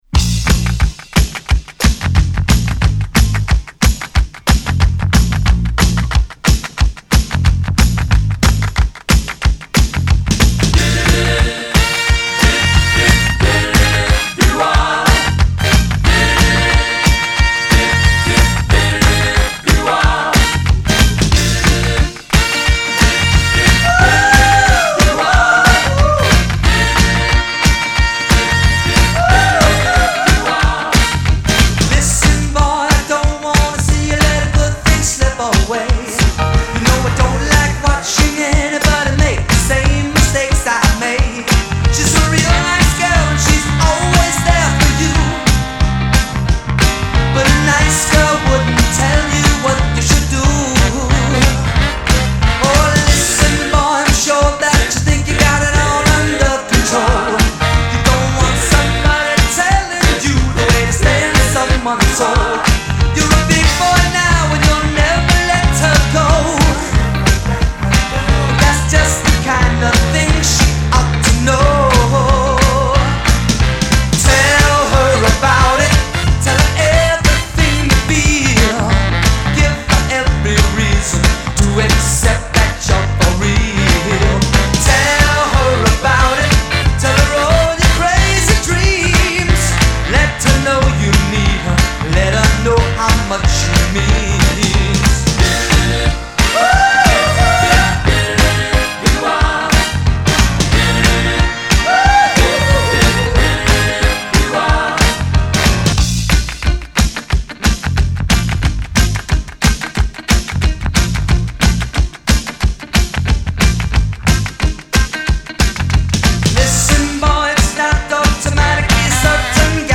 special extended remix